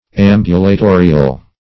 Ambulatorial \Am`bu*la*to"ri*al\, a.
ambulatorial.mp3